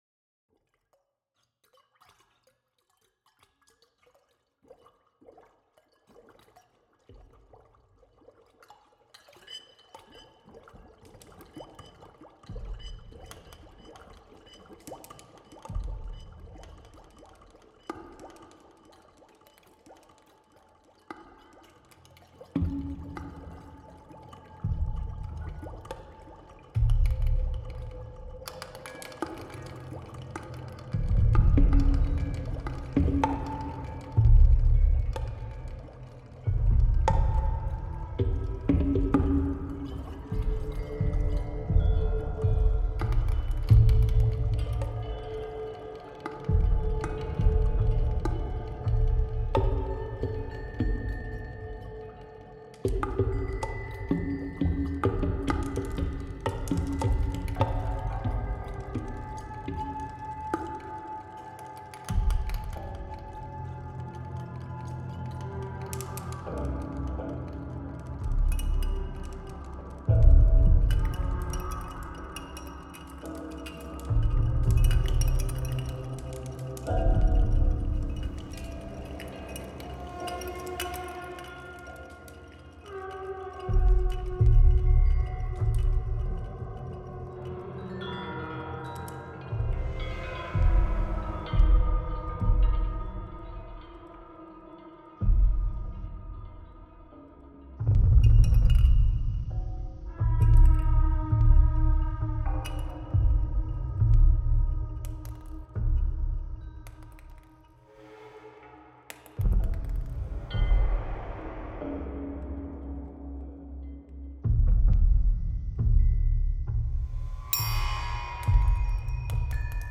Apollon joue sur un tympanum démesuré.
Une par exécutant, onze dieux se partagent les instruments.